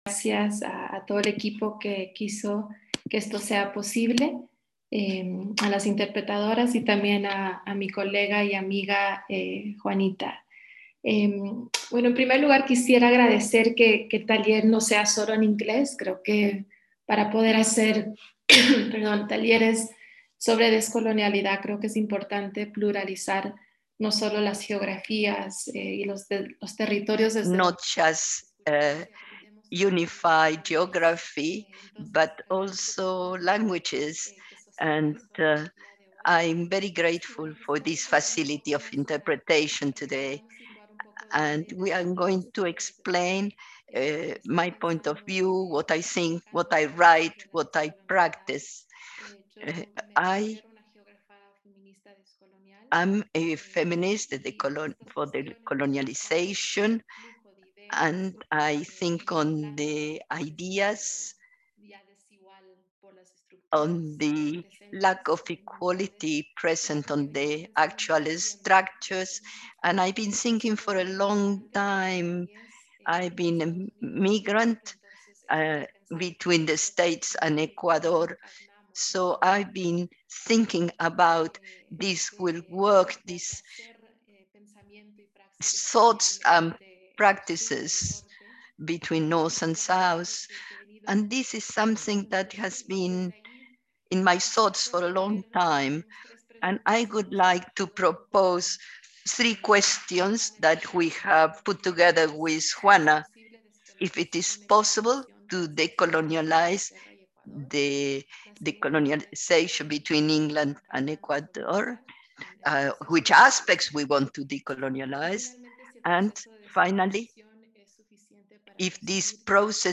Sobre la presentación (que fue seguida por una discusión participativa):